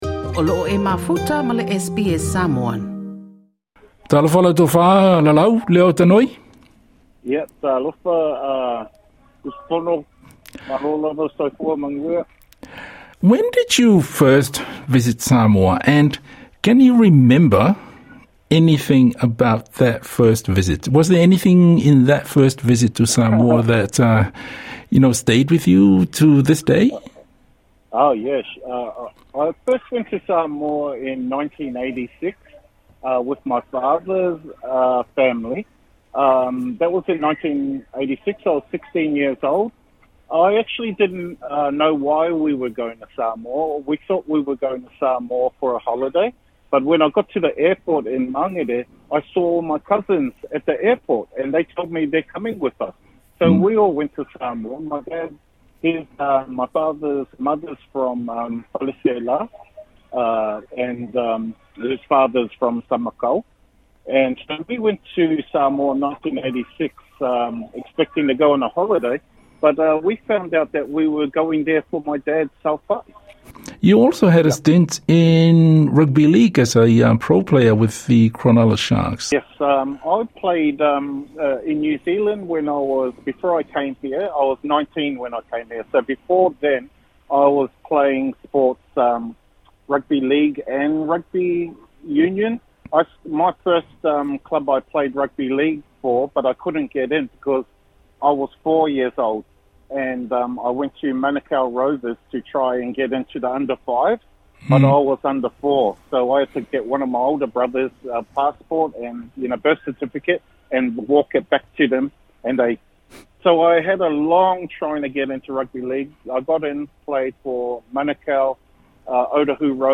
In this interview he shares some of the highlights on a career in rugby league, including a stint with the Cronulla Sharks club, and his youth work that opened a door for him to work as a curator and facilitator of Pasefika Art exhibitions.